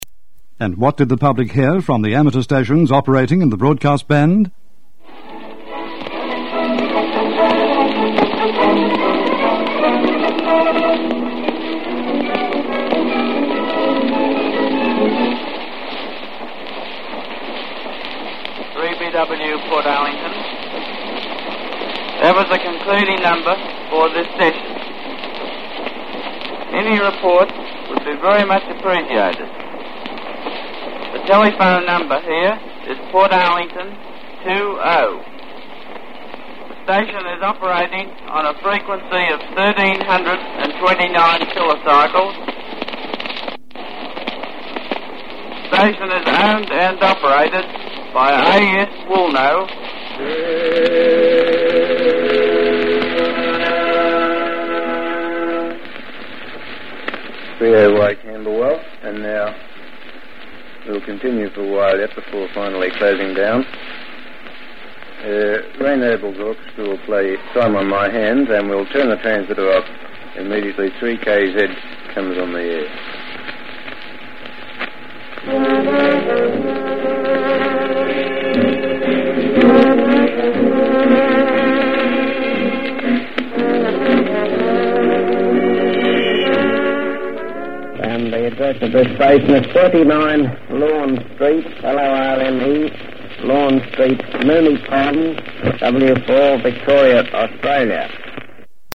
This recorder scribed the signal grooves directly into the soft aluminium disc.
In the first segment, three experimenters are heard identifying their stations.